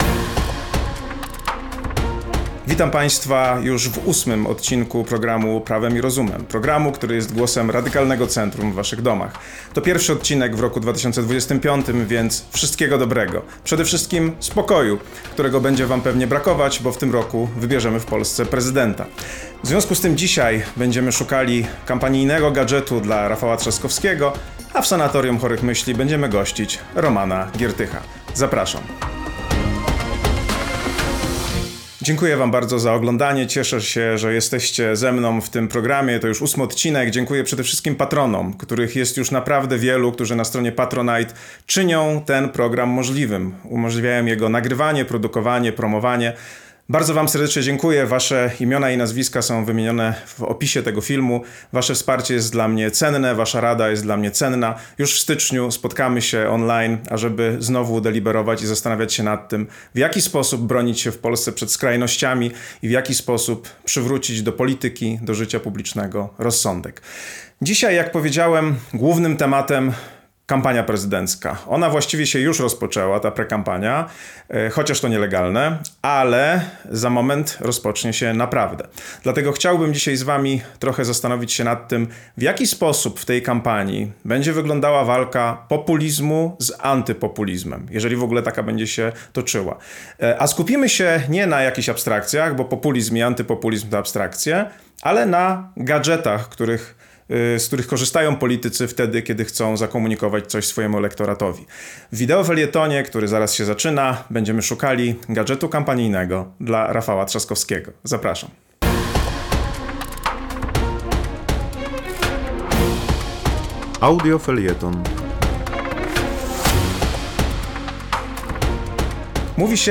Na te pytania i wiele innych odpowiadamy w wykładzie o dynamicznej i statycznej wykładni prawa.